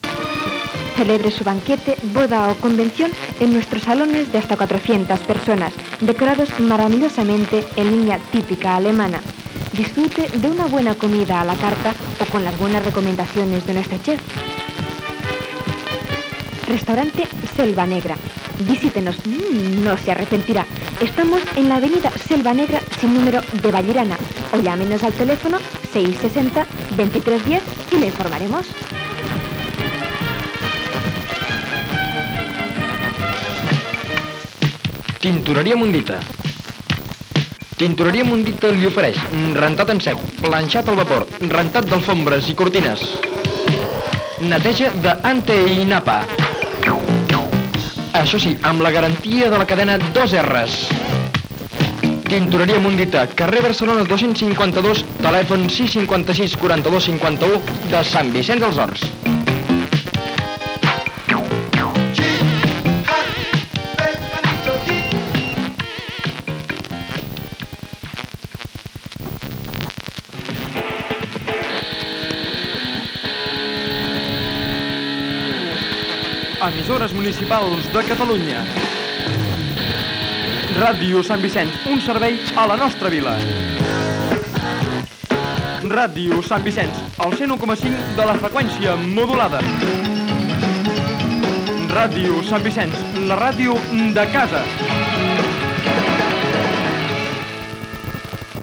Publicitat i indicatiu de l'emissora
FM